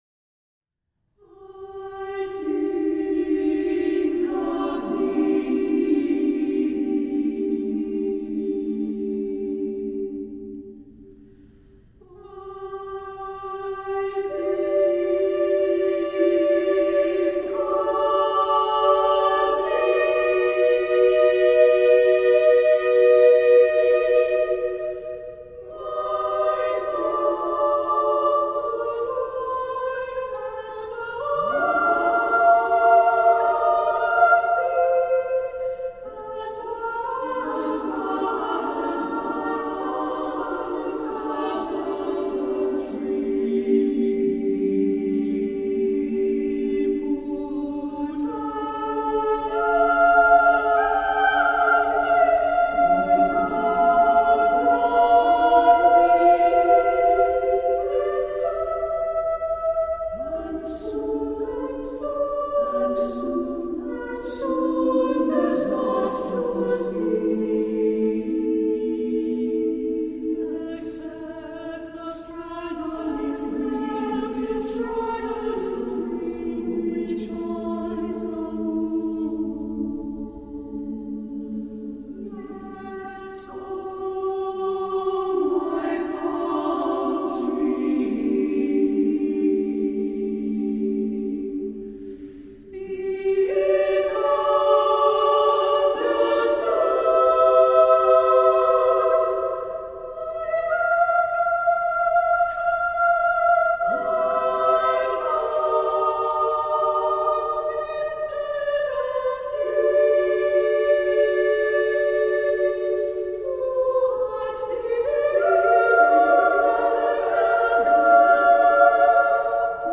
is dynamically dressed with a rich palette of word painting.
SSA a cappella